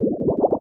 06515 bubble interface ding
bubble click ding interface menu pop select sfx sound effect free sound royalty free Sound Effects